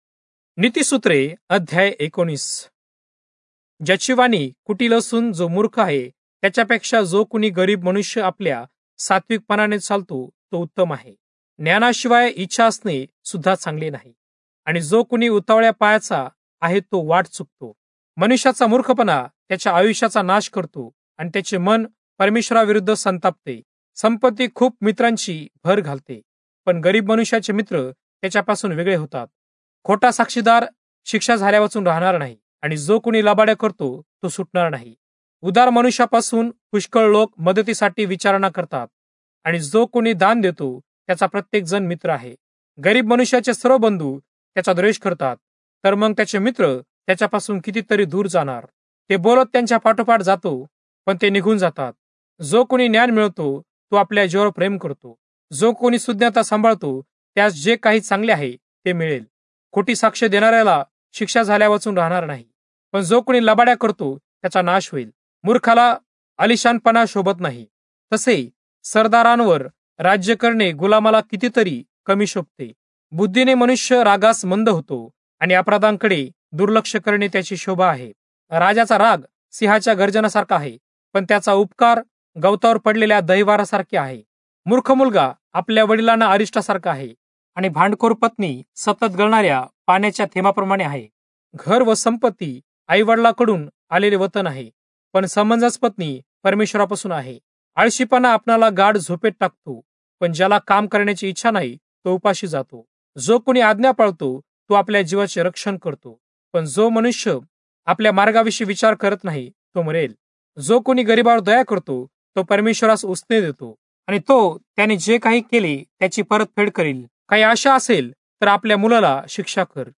Marathi Audio Bible - Proverbs 7 in Irvmr bible version